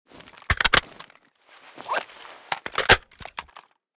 ak74_reload.ogg.bak